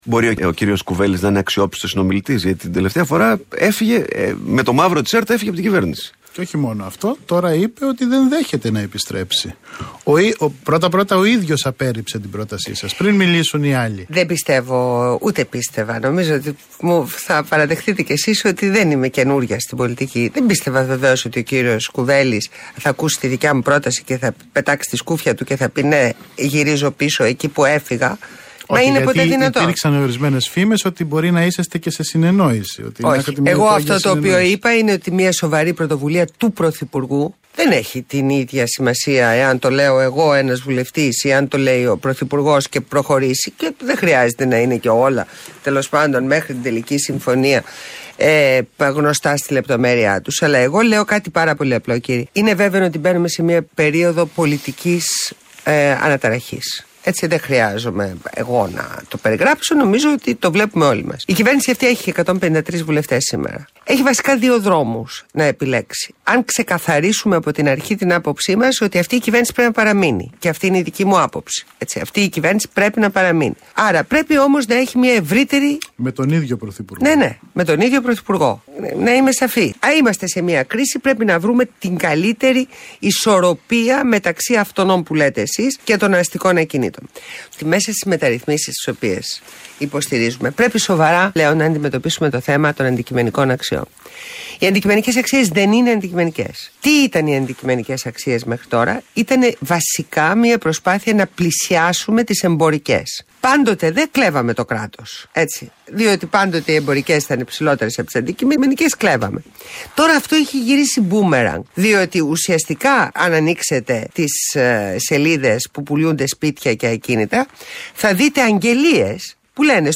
Ακούστε τη συνέντευξη της Ντόρας Μπακογιάννη